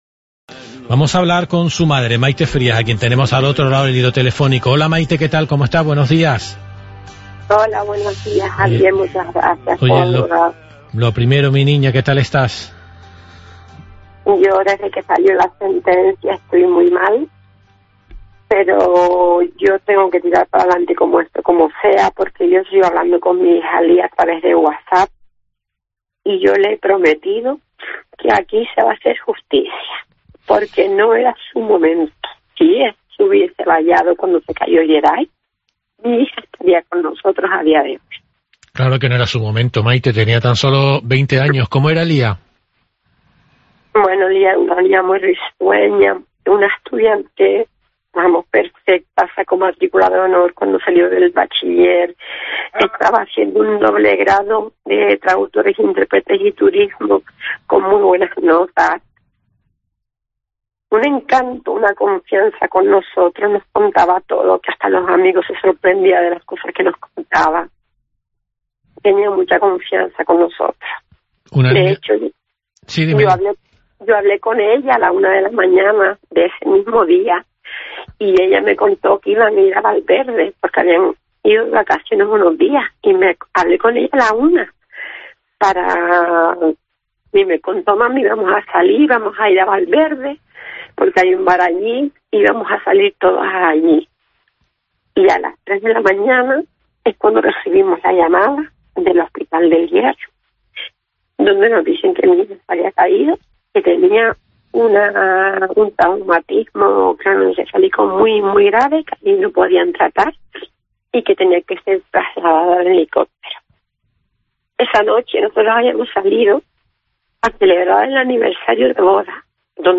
hablamos con la madre